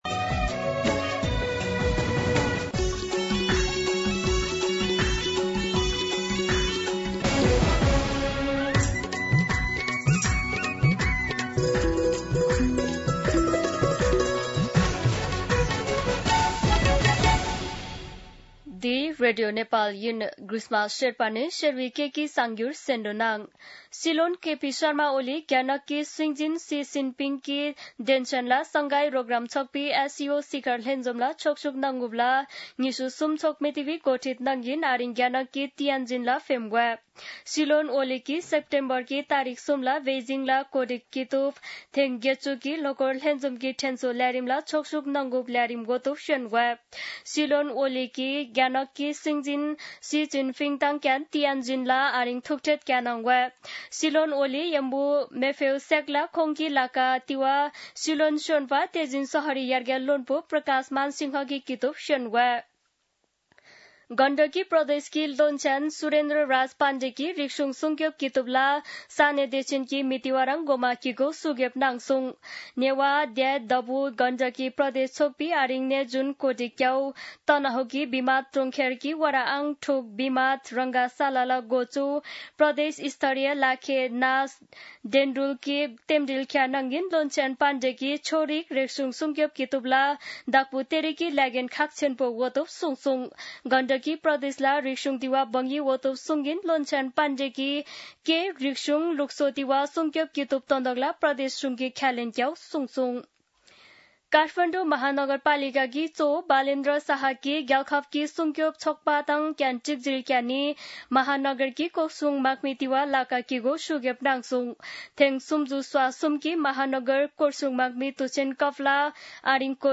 शेर्पा भाषाको समाचार : १४ भदौ , २०८२
Sherpa-News-05-14.mp3